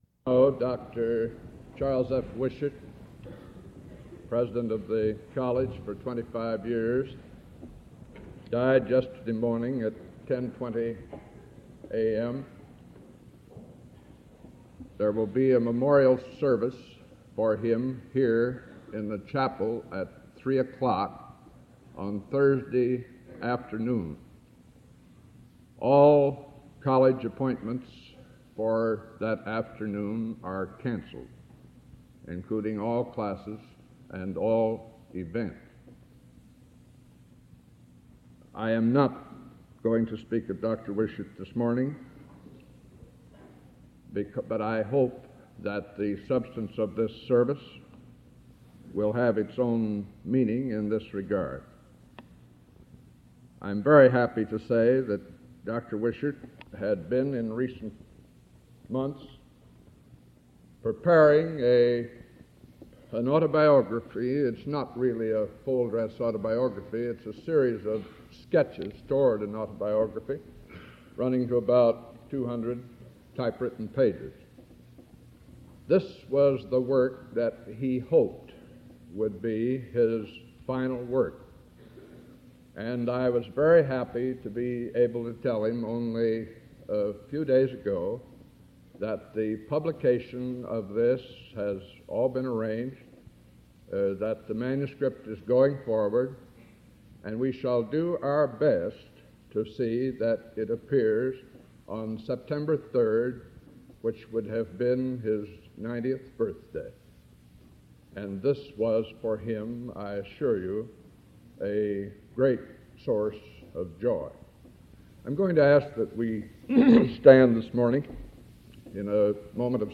"Chapel Address" Tuesday March 7